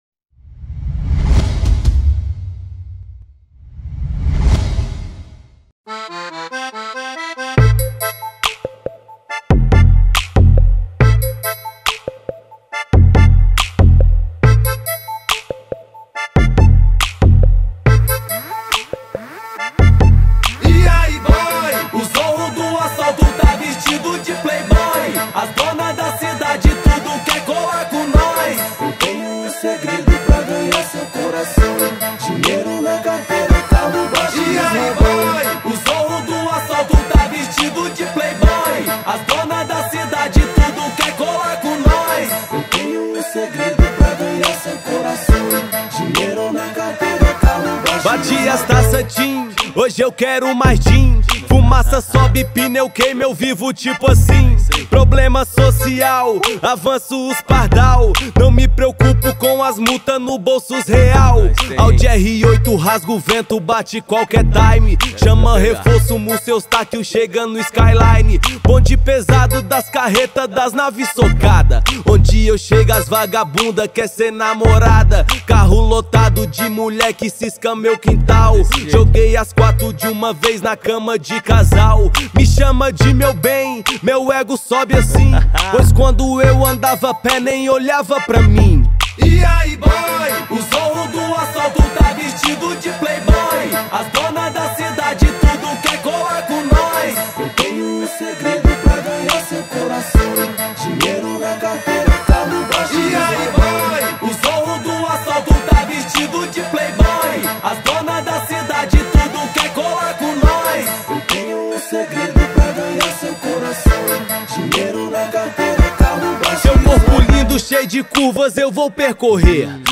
2024-05-07 23:37:25 Gênero: Rap Views